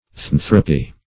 Theanthropy \The*an"thro*py\, n.